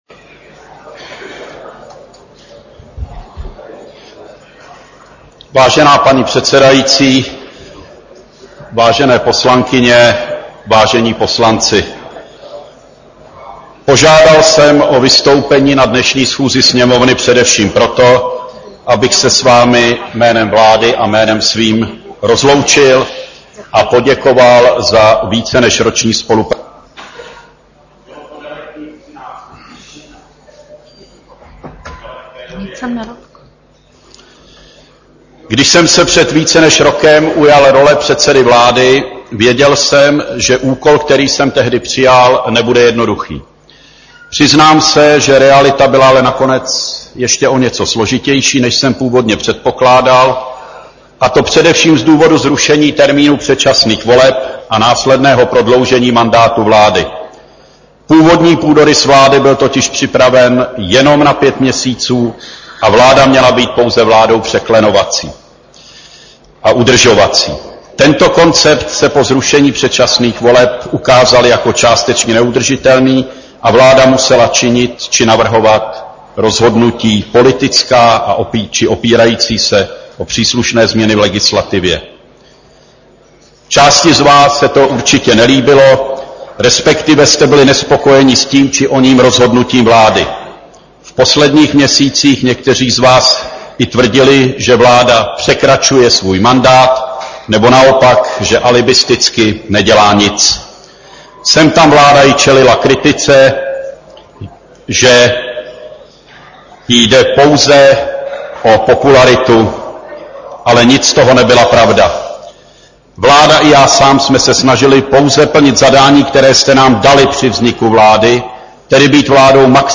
Projev Jana Fischera na poslední schůzi Poslanecké sněmovny